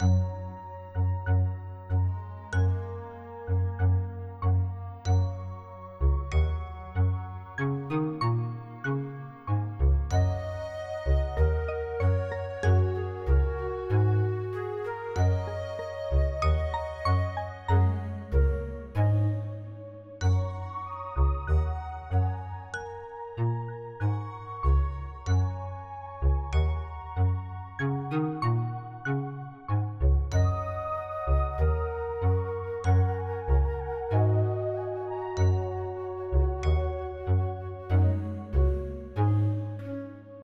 add happy theme